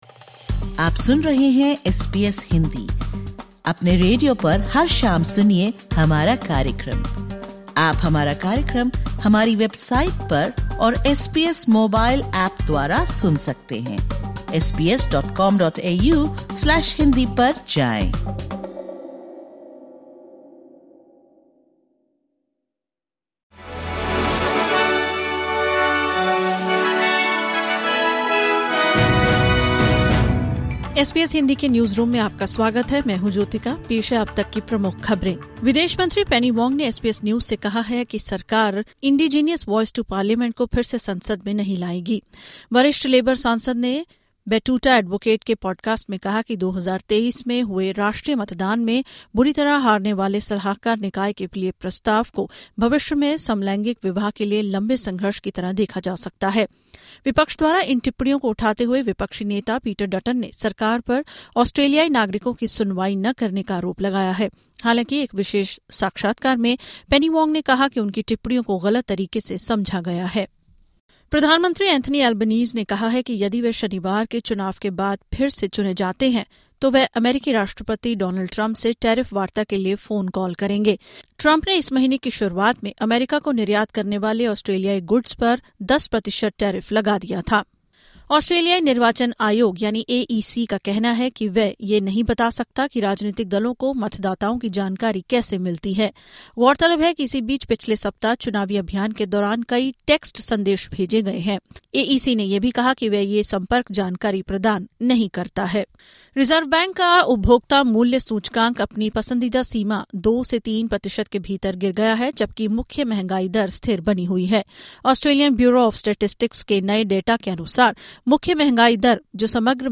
Listen to the top News of 30/04/2025 from Australia in Hindi.